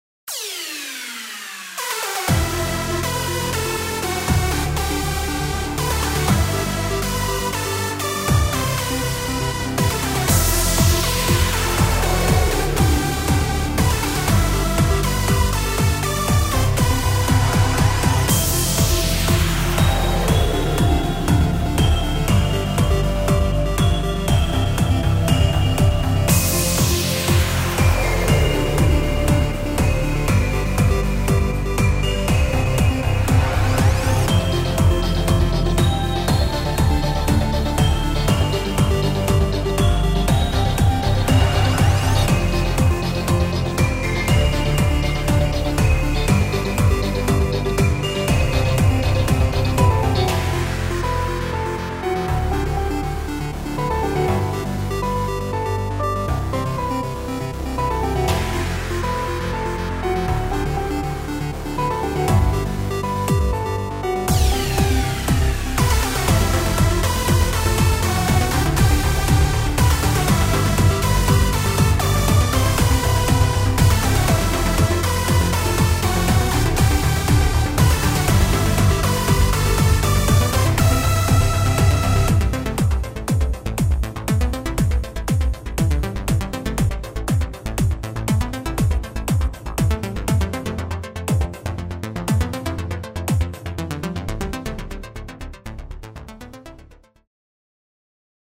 フリーBGM 戦闘曲
どこか懐かしくも、未来的な都市の夜景を思わせるデジタル・エモ。
• テンポ：約150BPM前後で疾走感あり
• ドラム：エレクトロニックで硬質なビート（ややブレイクビーツ風）
• シンセサウンド：中域に厚みを持たせたリードとサイドチェインを効かせたパッドが主役
• コード進行：エモーショナルなマイナーコード中心でサビ的な部分では転調的変化も
• ミックス：低音を抑えつつ中高域に張りを持たせ映像と干渉しにくい構成